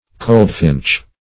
coldfinch - definition of coldfinch - synonyms, pronunciation, spelling from Free Dictionary Search Result for " coldfinch" : The Collaborative International Dictionary of English v.0.48: Coldfinch \Cold"finch`\, n. (Zool.) A British wagtail.